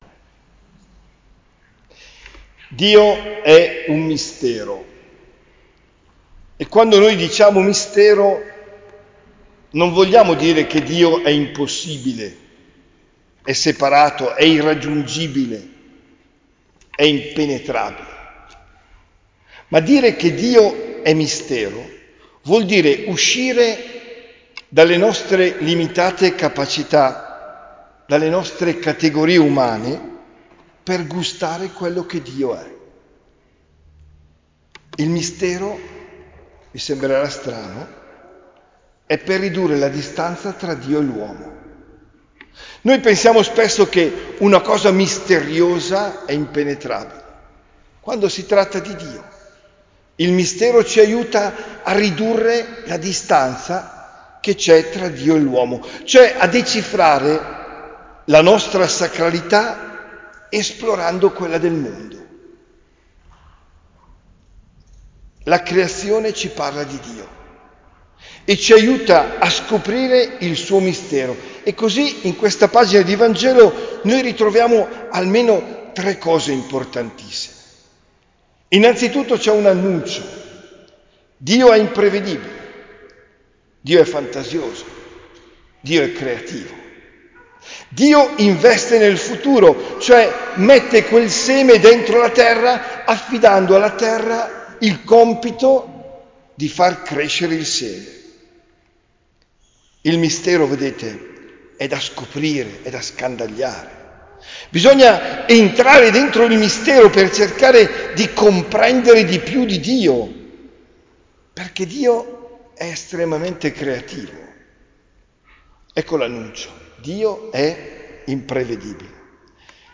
OMELIA DEL 16 GIUGNO 2024